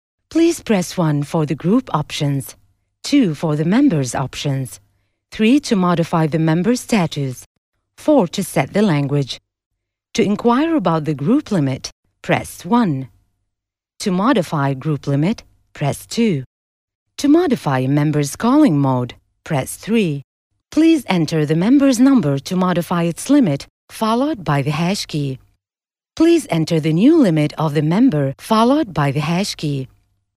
Voice Description: Real Perky Warm Sexy Young Mom Energetic Attitude Articulate Smart IN ONE WORD : ( Professional )
Arabic female voice over, UAE voice over, Professional female voiceover artist, voice over talent, Arabic voice over
Sprechprobe: Sonstiges (Muttersprache):